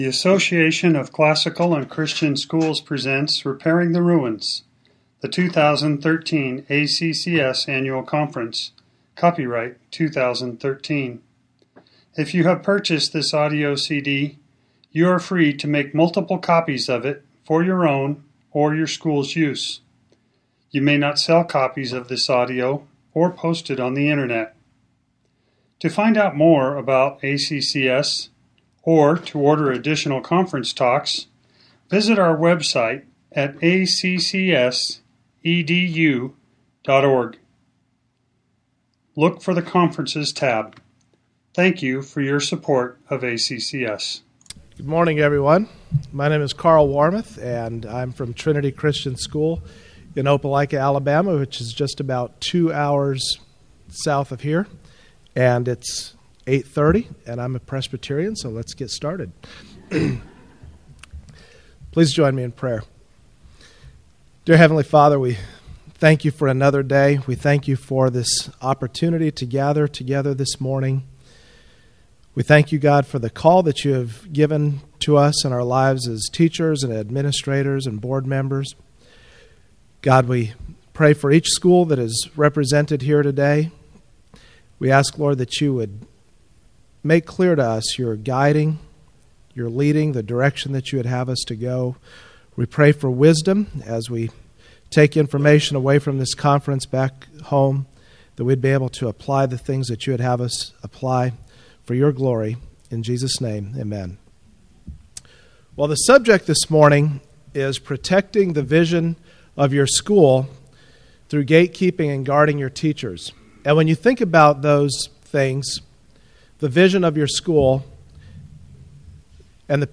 2013 Workshop Talk | 1:02:55 | All Grade Levels, Leadership & Strategic
The Association of Classical & Christian Schools presents Repairing the Ruins, the ACCS annual conference, copyright ACCS.